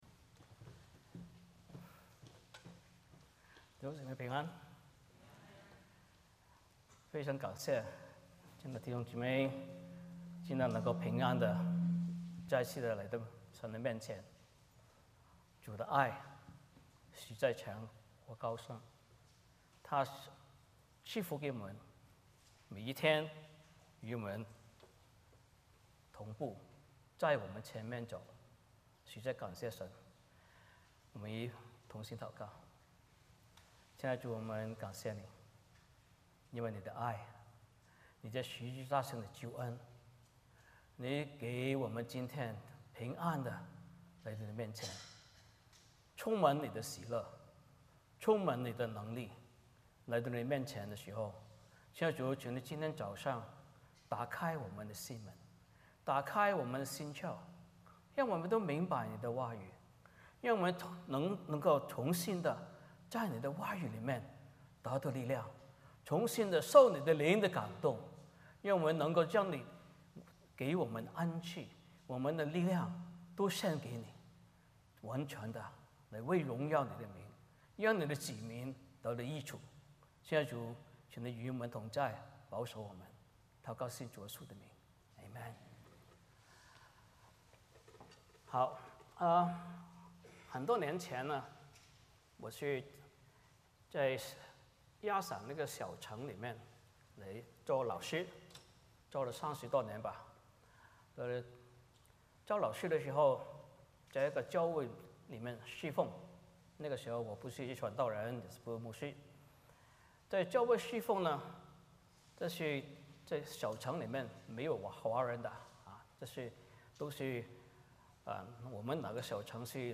哥林多前书 12:1-13 Service Type: 主日崇拜 欢迎大家加入我们的敬拜。